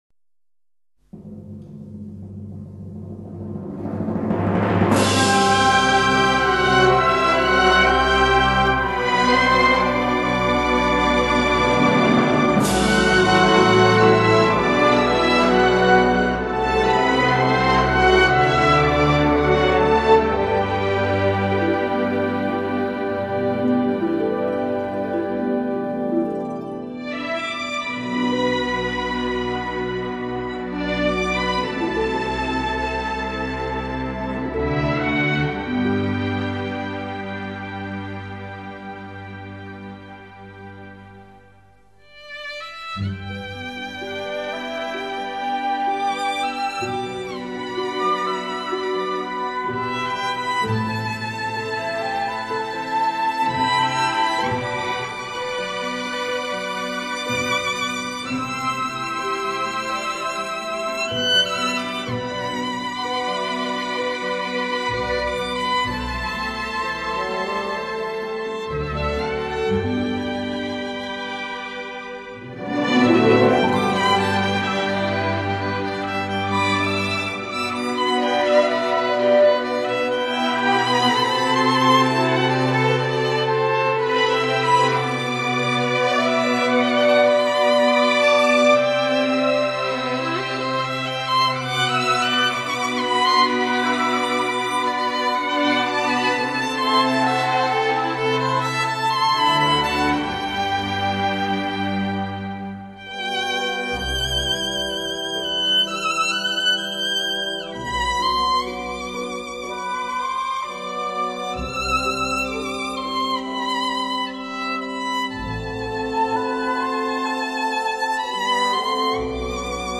本輯所選之曲目，著意於中國名作和經典篇章，全新配器，旋律剛勁優美，聽來如泣如訴，回味無窮。